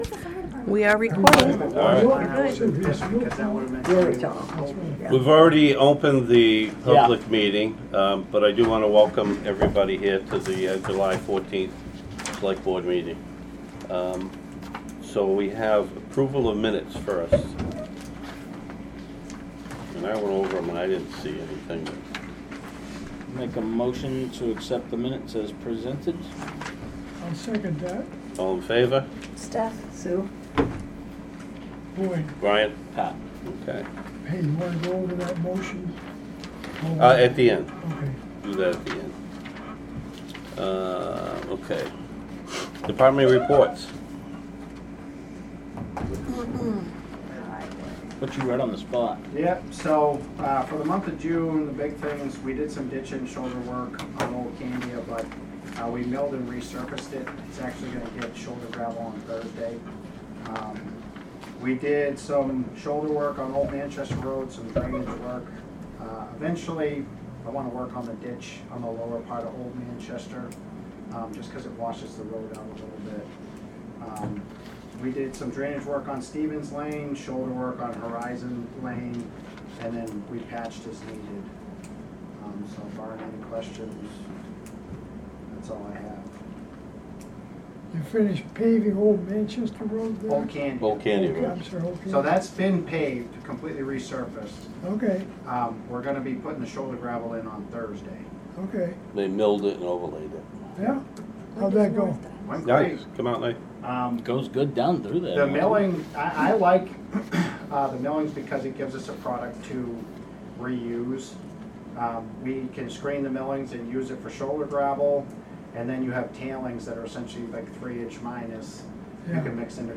Audio recordings of committee and board meetings.
Board of Selectmen Meeting